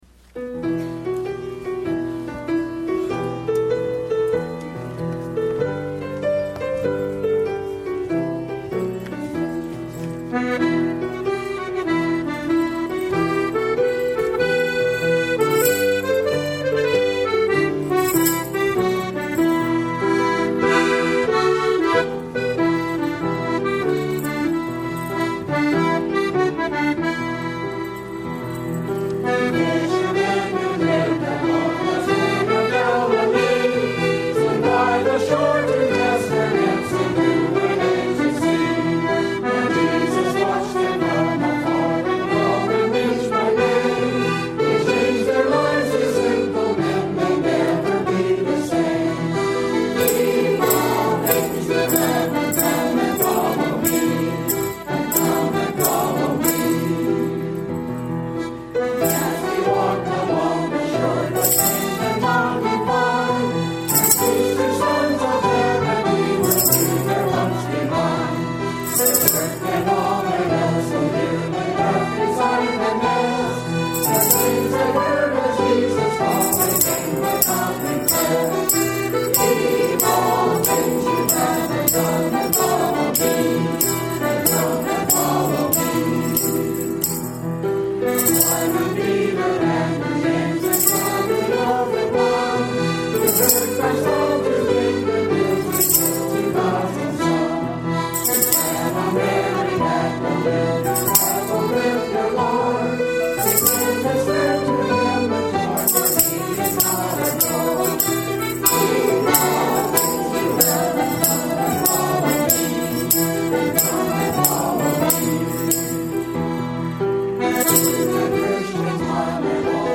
Arr. by Suzanne Toolan GC2 693 Two Fishermen.pdf GC2 693 Two Fishermen - Bb Instruments.pdf GC2 693 Two Fishermen - C Instruments.pdf GC2 693 Two Fishermen - TUMC.mp3 - As performed by the United Methodist Church, Chico CA Gather Comprehensive 2 #693